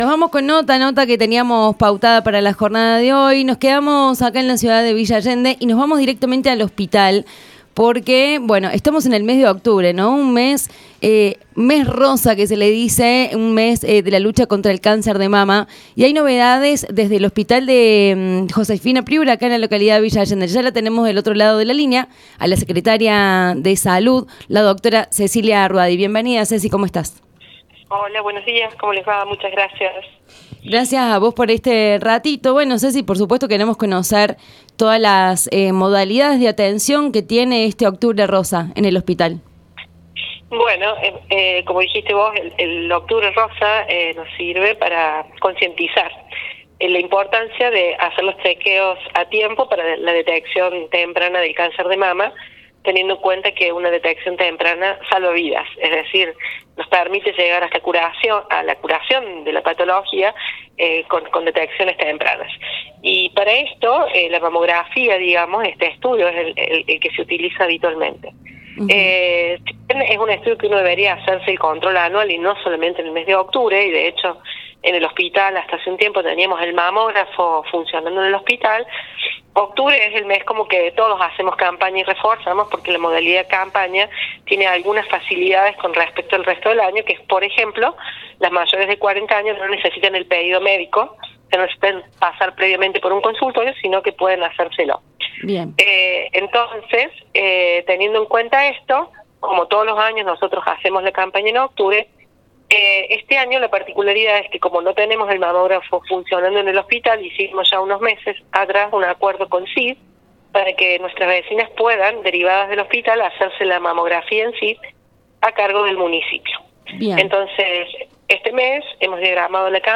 ESCUCHA LA NOTA COMPLETA CON CECILIA ROUADI EN ANEXADOS